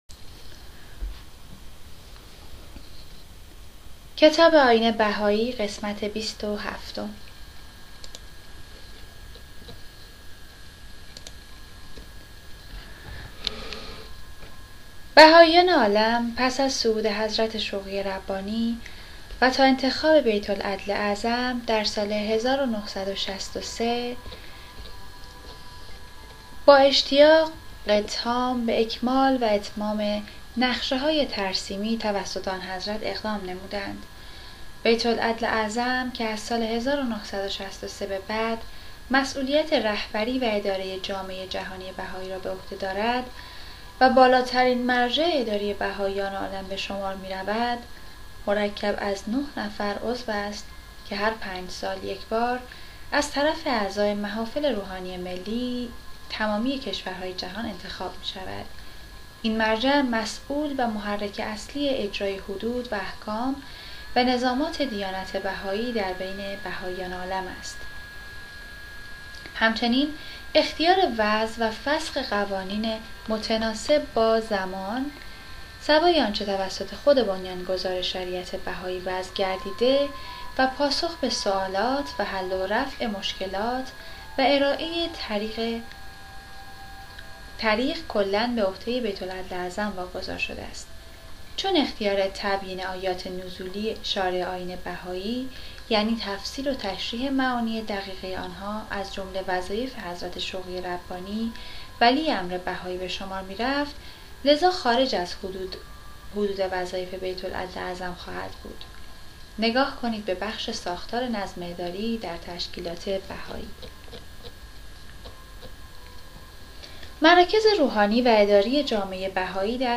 کتاب صوتی «نگرشی کوتاه به تاریخ و تعالیم دیانت بهائی» | تعالیم و عقاید آئین بهائی